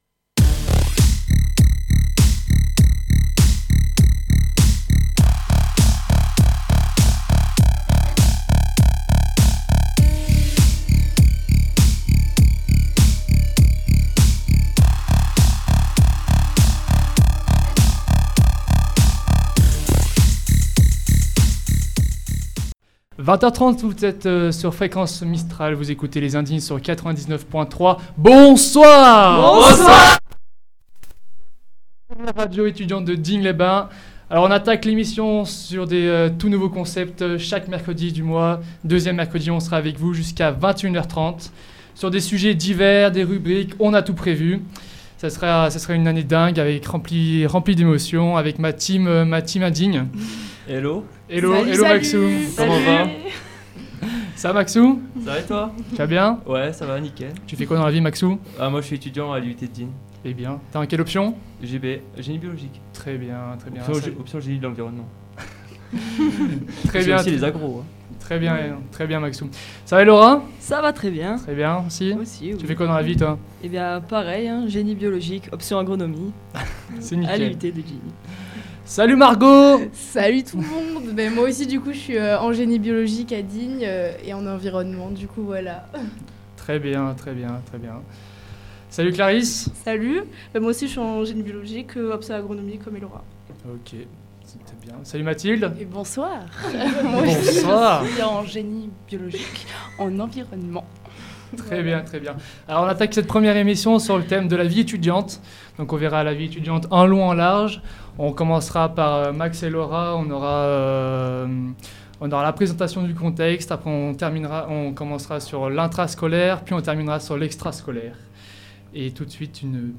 1ère émission en direct sur Fréquence Mistral Digne 99.3 avec les étudiants en Génie Biologique 1ère année